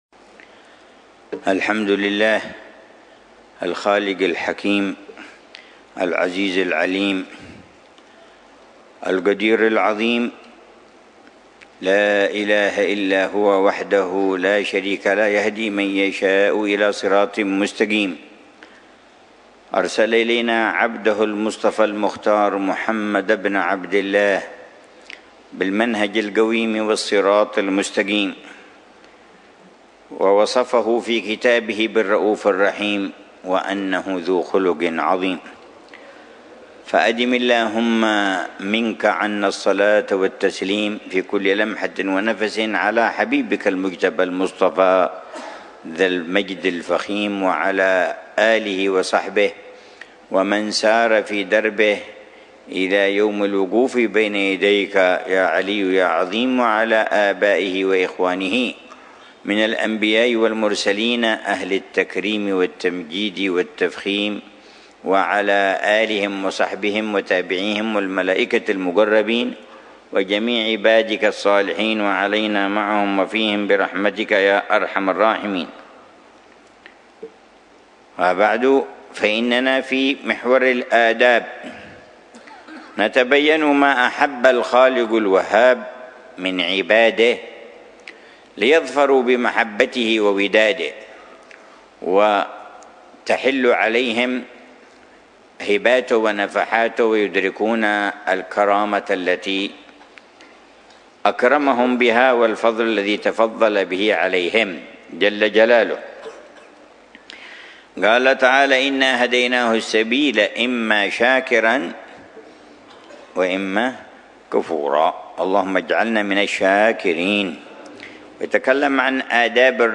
الدرس الحادي والأربعون من شرح العلامة الحبيب عمر بن حفيظ لكتاب الأدب في الدين لحجة الإسلام الإمام محمد بن محمد الغزالي، ضمن الدروس الصباحية لأ